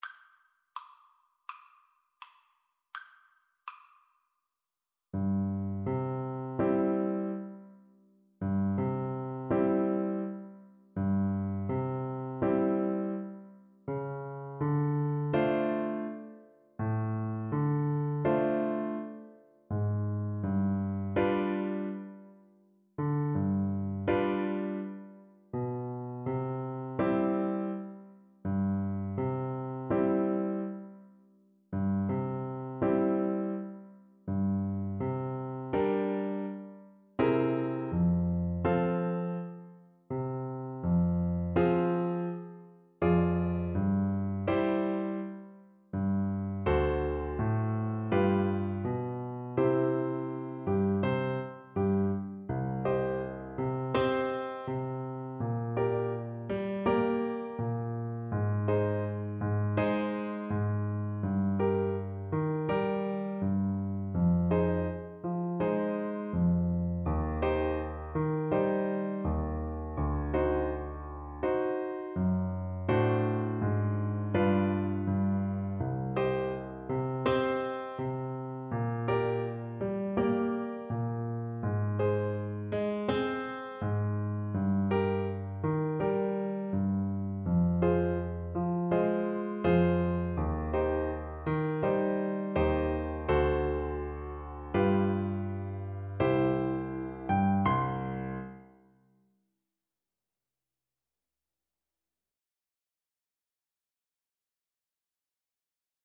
tango song
2/4 (View more 2/4 Music)
World (View more World French Horn Music)